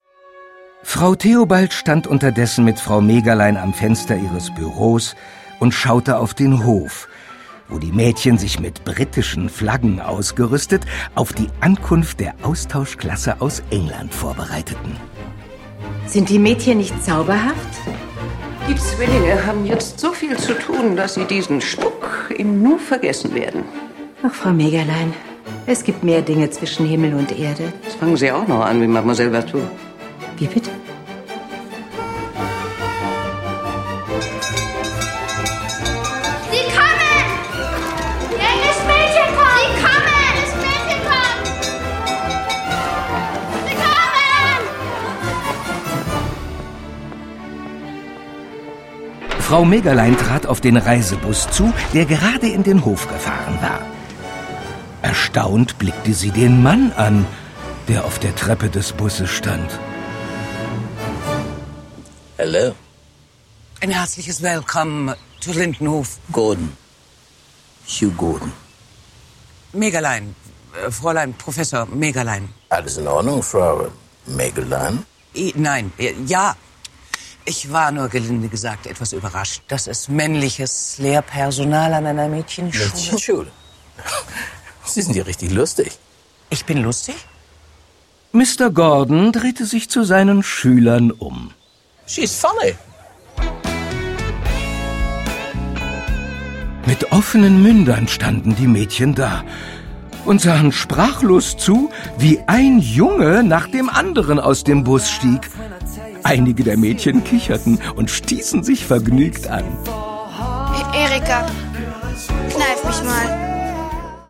Hanni und Nanni - Das Original-Hörspiel zum Kinofilm 3